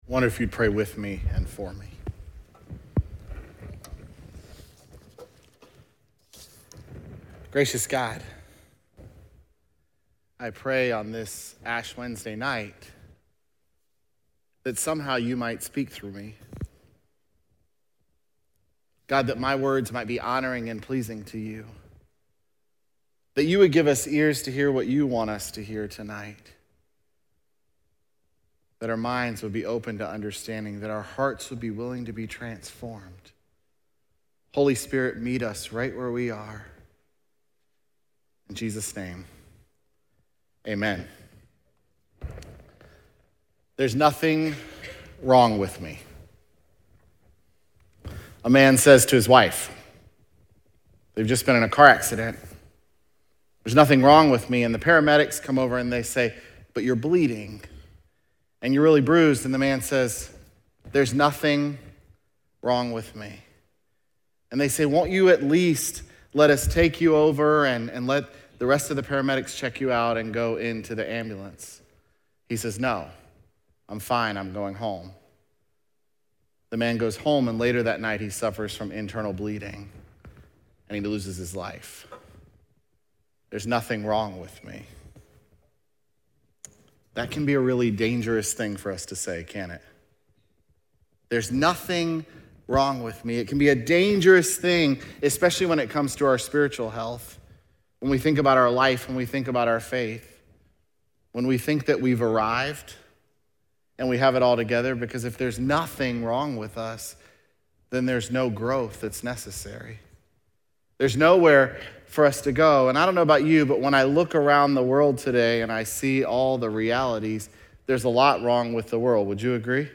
feb22sermon.mp3